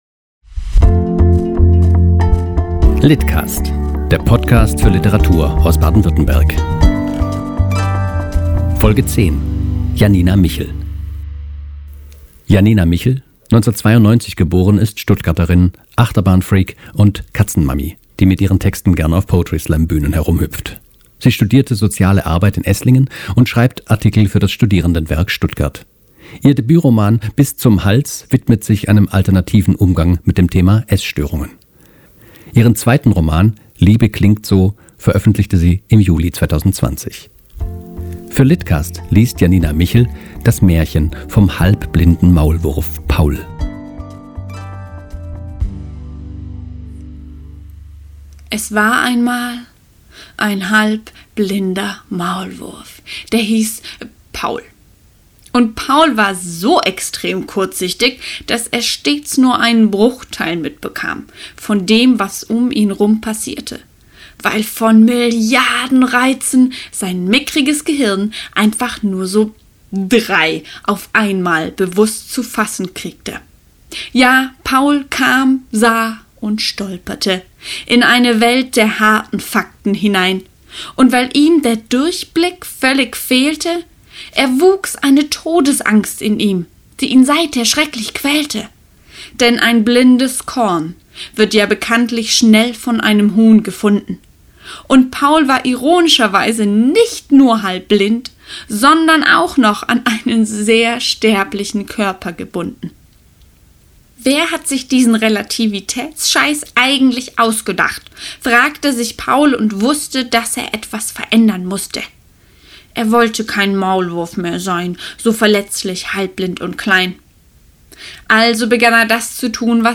liest "Das Märchen vom halbblinden Maulwurf"